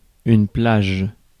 Ääntäminen
IPA : /sænd/